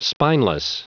Prononciation du mot spineless en anglais (fichier audio)
Prononciation du mot : spineless